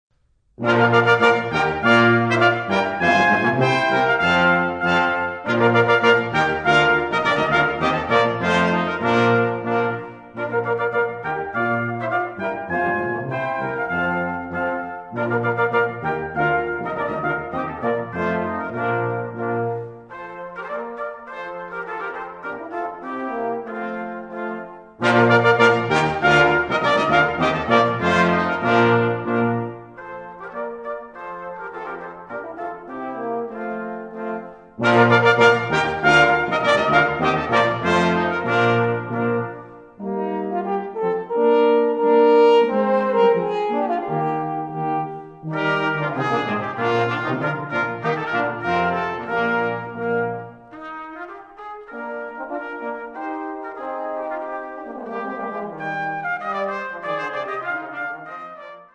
Letét: rézfúvós kvintett